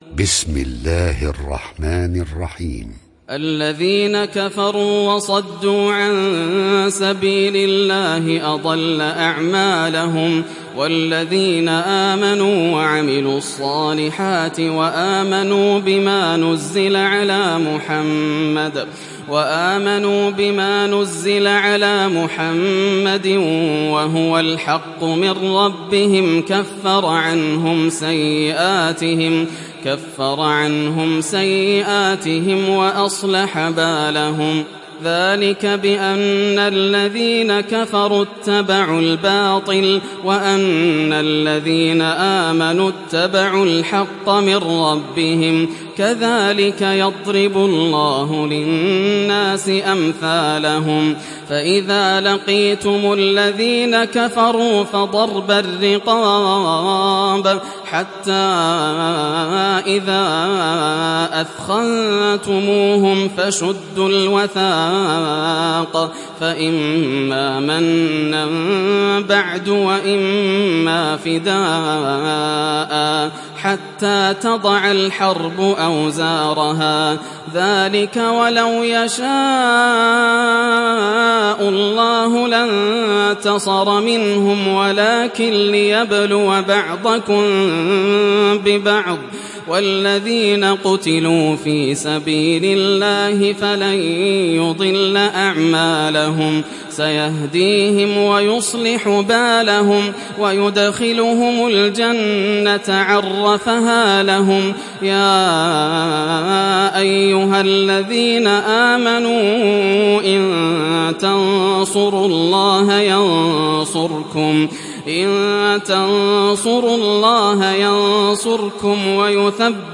Surat Muhammad Download mp3 Yasser Al Dosari Riwayat Hafs dari Asim, Download Quran dan mendengarkan mp3 tautan langsung penuh